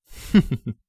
XS嘲弄1.wav
XS嘲弄1.wav 0:00.00 0:00.89 XS嘲弄1.wav WAV · 77 KB · 單聲道 (1ch) 下载文件 本站所有音效均采用 CC0 授权 ，可免费用于商业与个人项目，无需署名。
人声采集素材